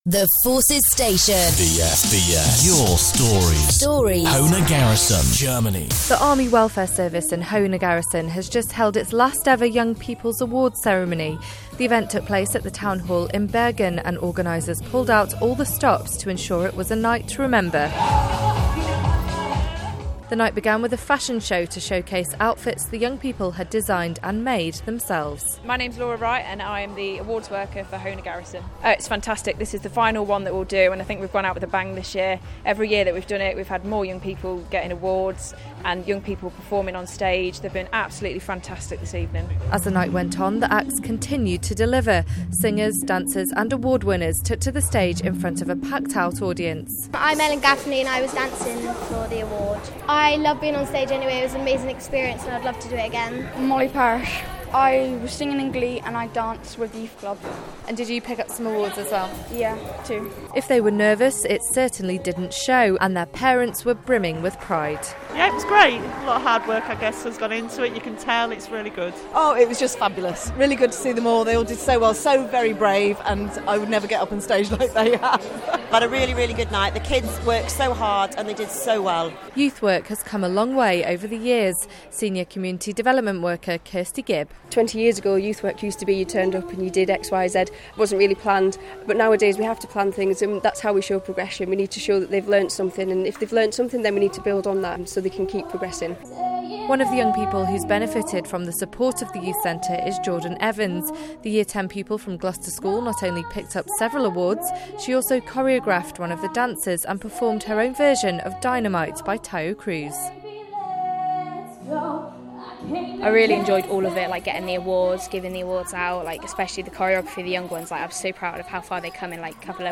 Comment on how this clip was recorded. The event took place at the Town Hall in Bergen and organisers pulled out all the stops to ensure it was a night to remember.